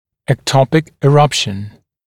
[ek’tɔpɪk ɪ’rʌpʃ(ə)n][эк’топик и’рапш(э)н]прорезывание в эктопированное положение